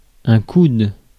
Ääntäminen
Ääntäminen France: IPA: /kud/ Haettu sana löytyi näillä lähdekielillä: ranska Käännös Konteksti Ääninäyte Substantiivit 1. elbow anatomia US 2. kink US Suku: m .